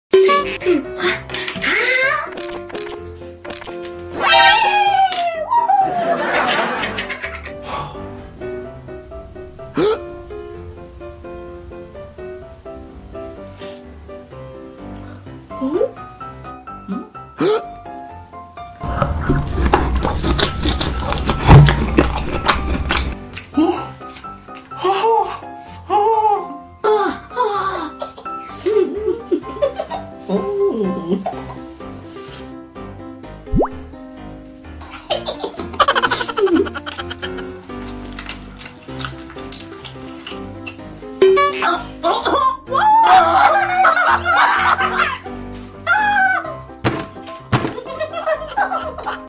Mukbang Funny Video